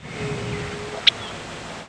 Main flight calls are a soft, clapping "pek" (or "check" or "trk"), a soft, rising "pwik", a louder, higher, huskier "jek" (or "jik"), a squeaky, doubled "ki-drk", and a whistled "seee" (or "seeer").
"Pwik" call from female in flight.